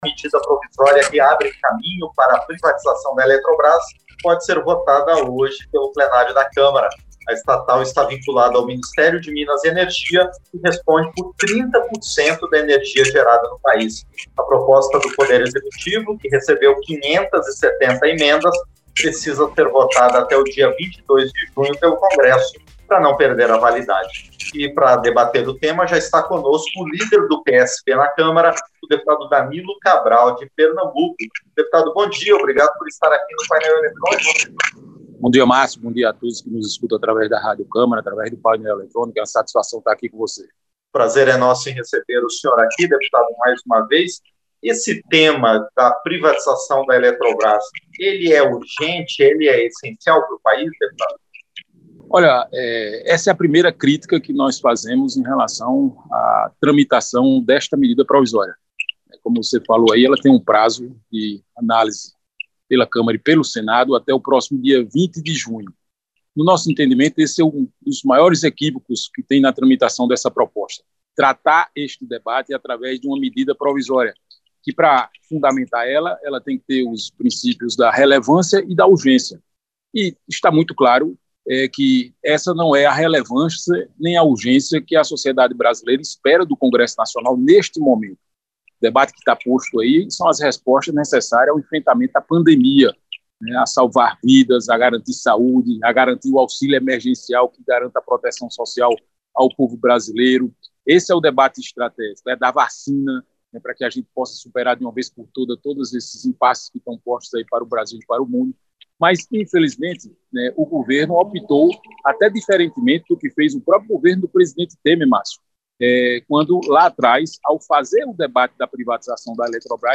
Entrevista - Dep. Danilo Cabral (PSB-PE)